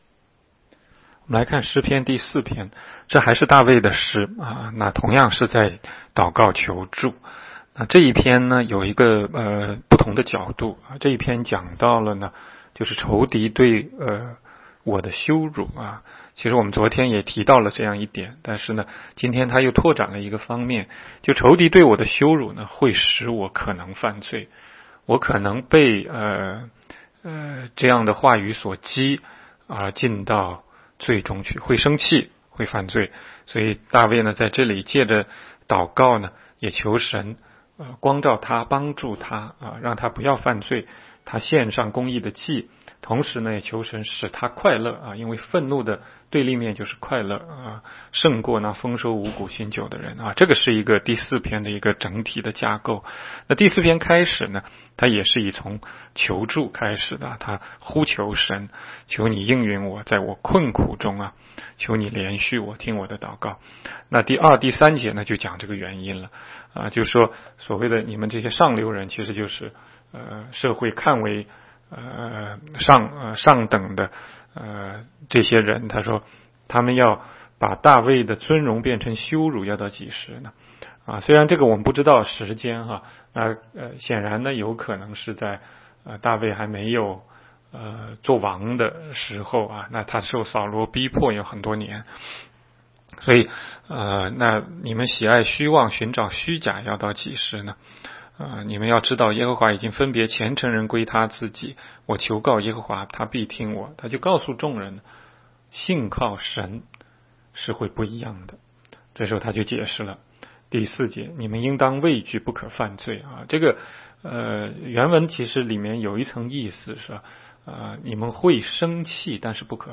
16街讲道录音 - 每日读经-《诗篇》4章